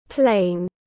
Plain /pleɪn/